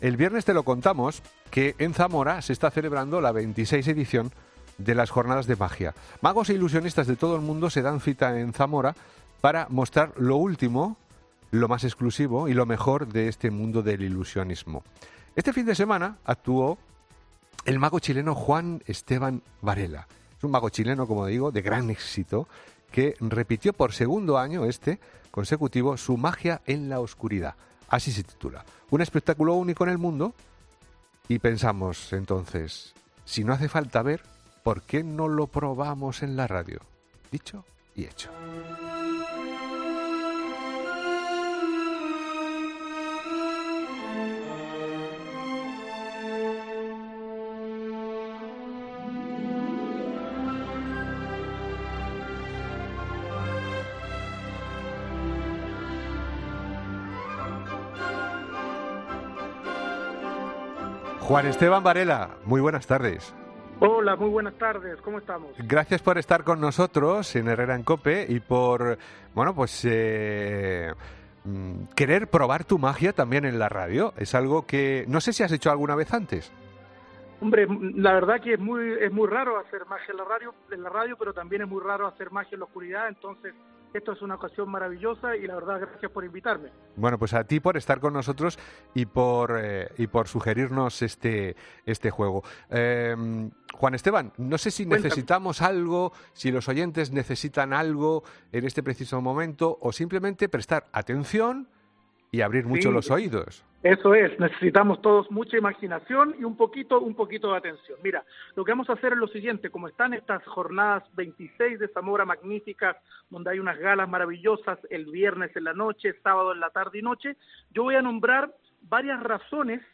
El truco de magia en directo que ha sorprendido a los oyentes de Herrera